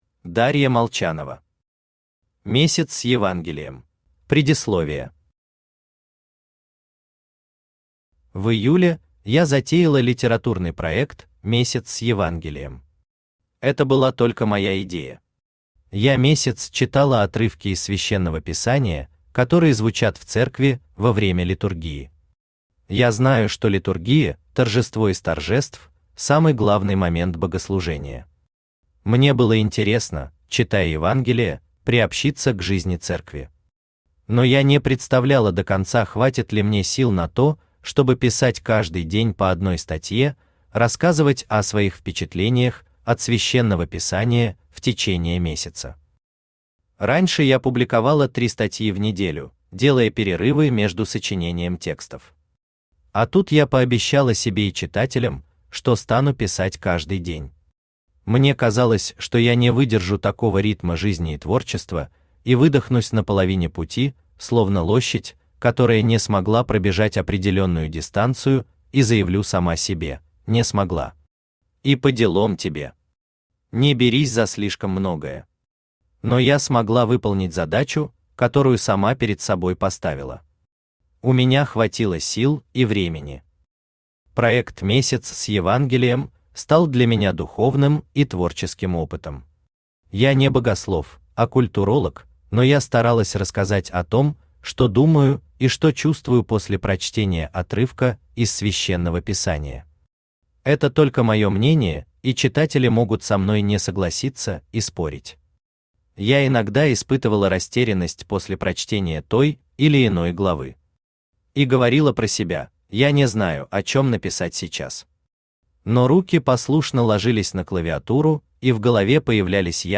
Аудиокнига Месяц с Евангелием | Библиотека аудиокниг
Aудиокнига Месяц с Евангелием Автор Дарья Сергеевна Молчанова Читает аудиокнигу Авточтец ЛитРес.